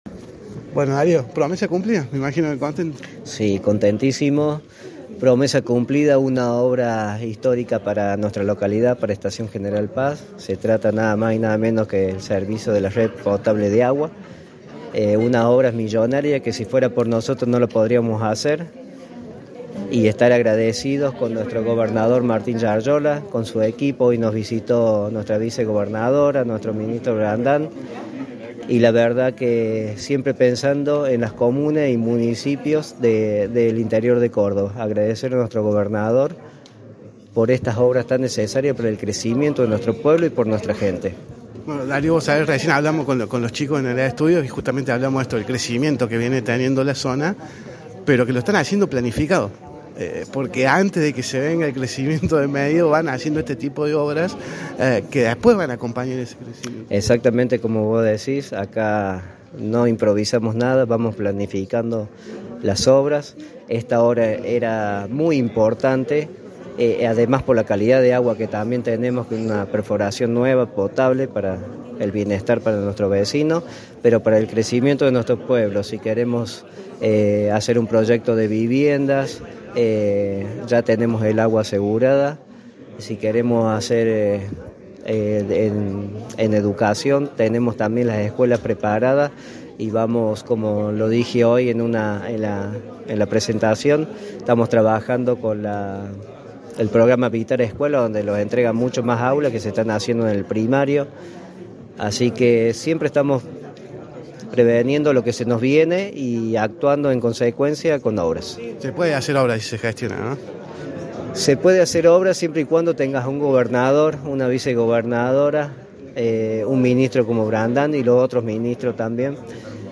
ENTREVISTA A DARIO ARATA, INTENDENTE DE ESTACIÓN GENERAL PAZ